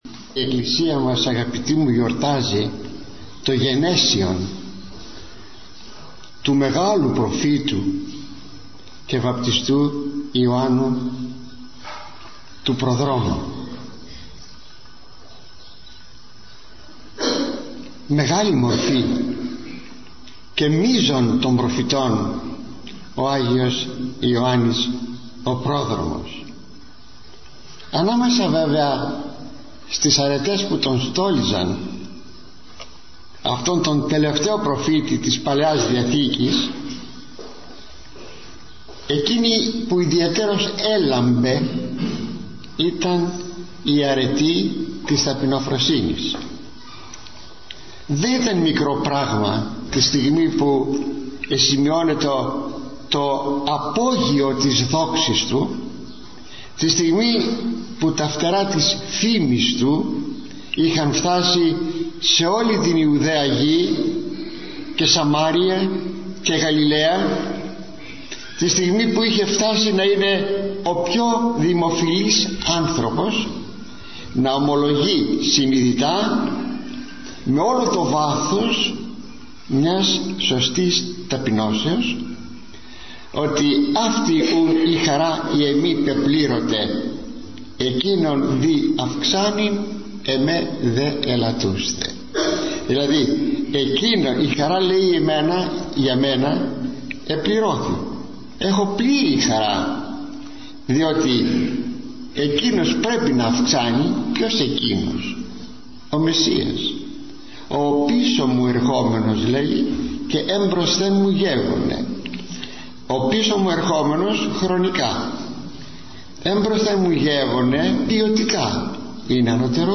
24 Ιουνίου, το Γενέσιον του Αγίου Ιωάννου του Προδρόμου – ηχογραφημένη ομιλία του Μακαριστού Αρχιμ.